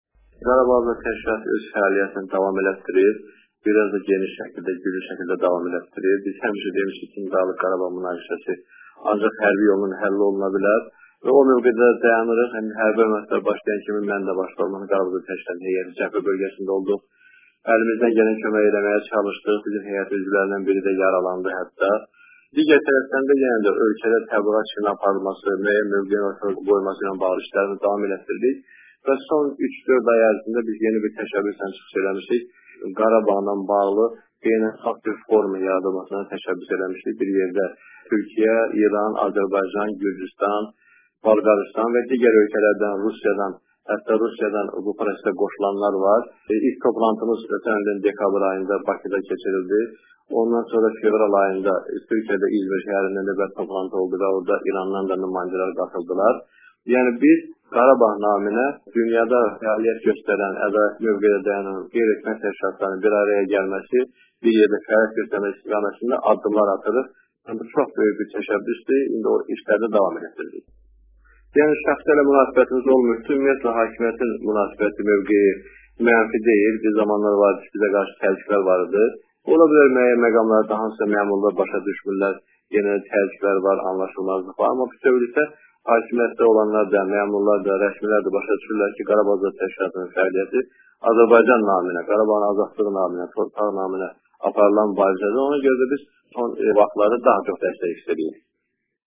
eksklüziv müsahibədə